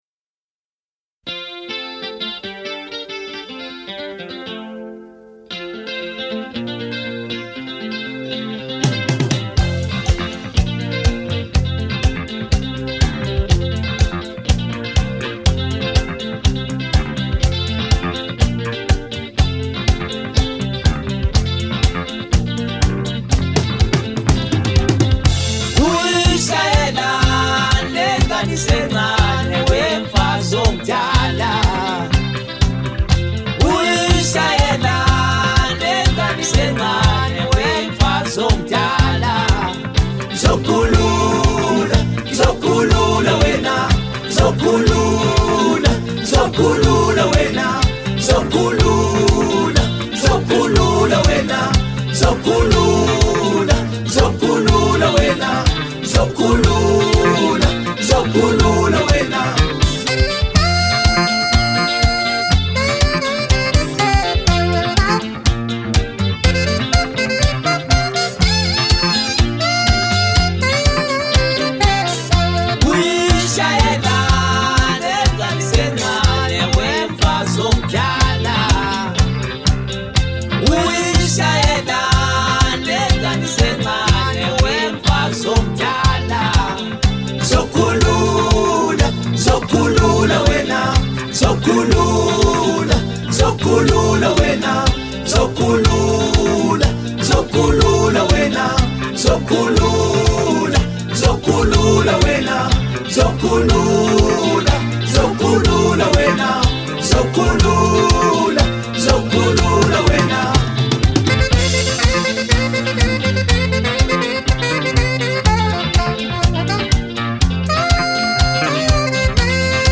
Wildly infectious.